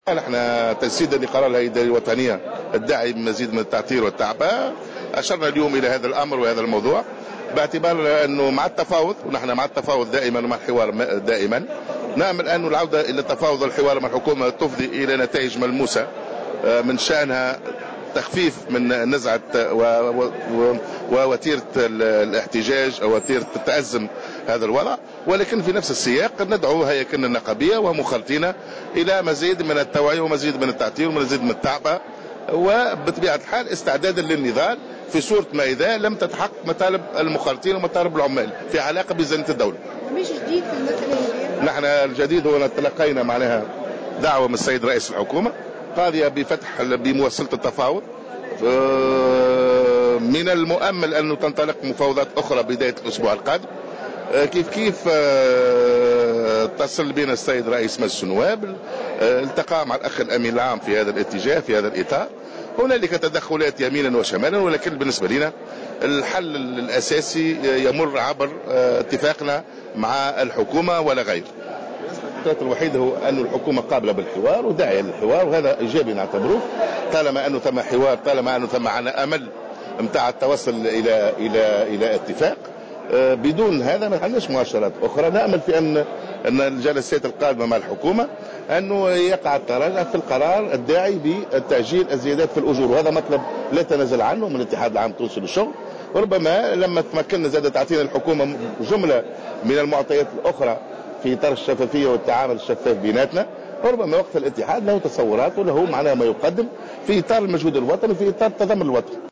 dans une déclaration accordée à Jawhara FM
En marge du Congrès ordinaire des universités de l’enseignement supérieur et de la recherche scientifique qui se tient aujourd’hui à Hammamet